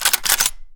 gun_rifle_cock_01.wav